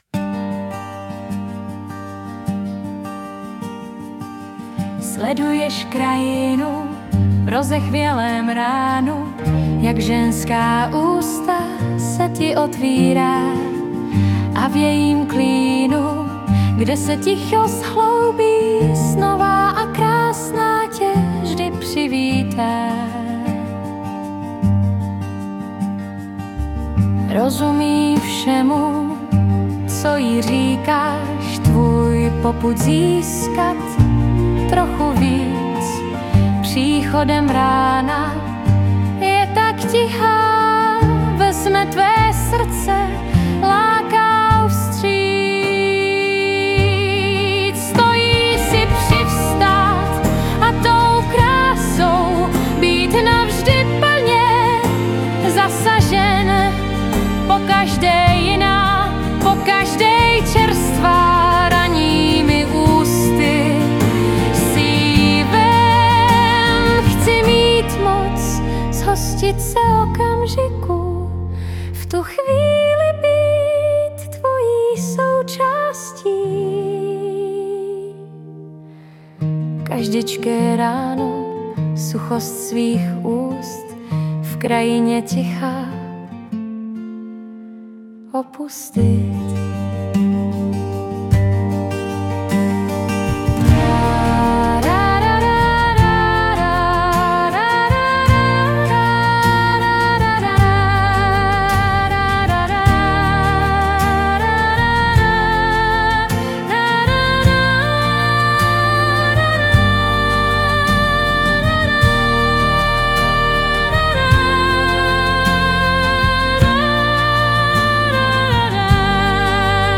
Anotace: * Lásce, čerstvá, Včera psaná i poslaná a nedalo mi to, ji opět zkusit dát AI ke zhudebnění..:)
Hudba a zpěv AI.